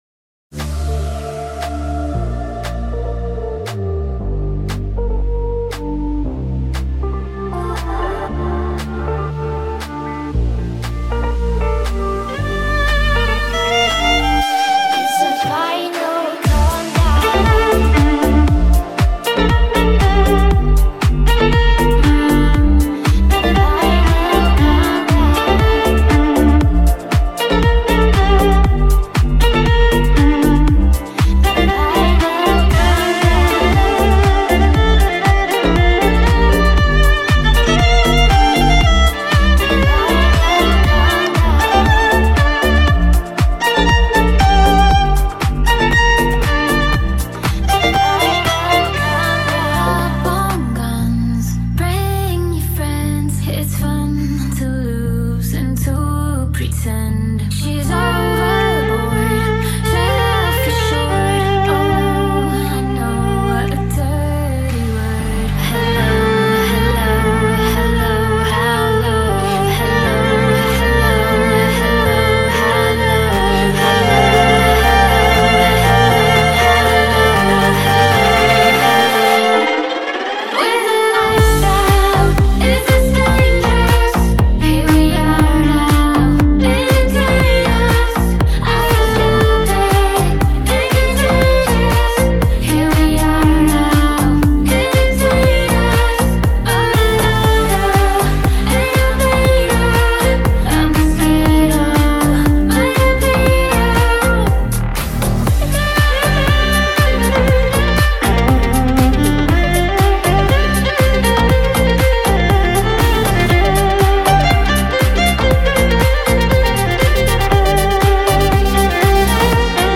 Violinist and DJ Duo